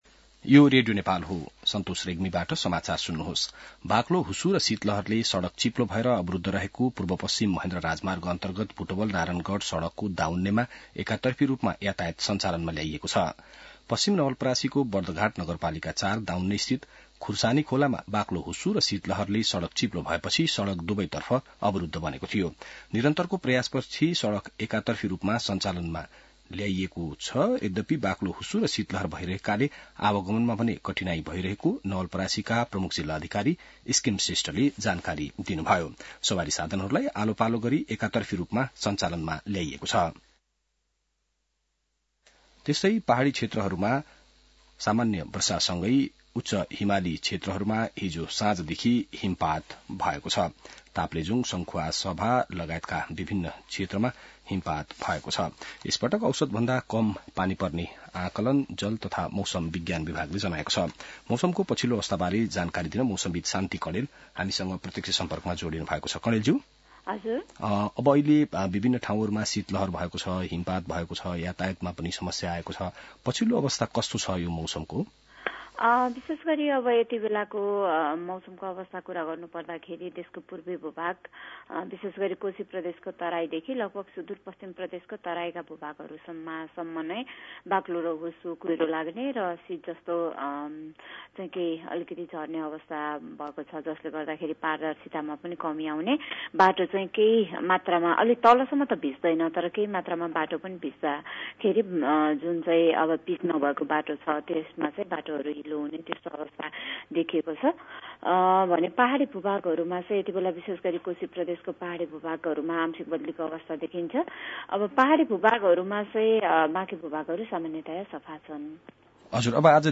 बिहान ६ बजेको नेपाली समाचार : २६ पुष , २०८१